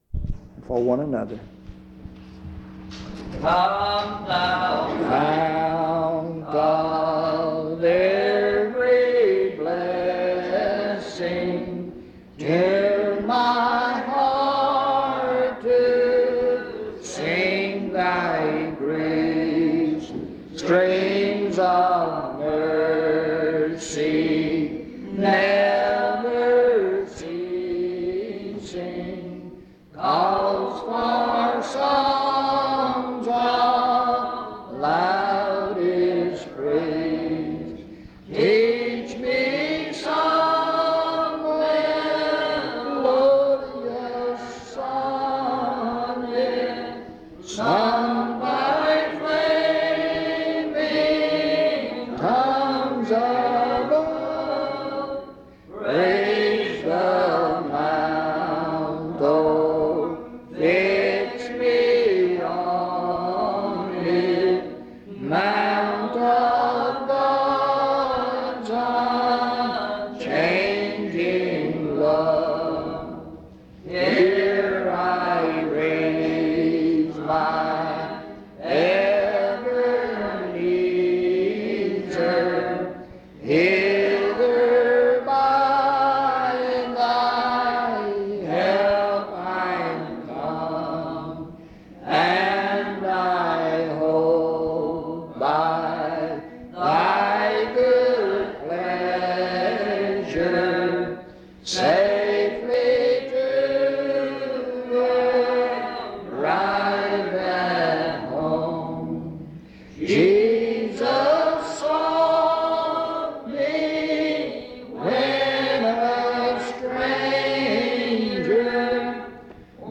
1 Peter 3:17-22; The latter portion of this service seems to be missing.
In Collection: Reidsville/Lindsey Street Primitive Baptist Church audio recordings Miniaturansicht Titel Hochladedatum Sichtbarkeit Aktionen PBHLA-ACC.001_022-A-01.wav 2026-02-12 Herunterladen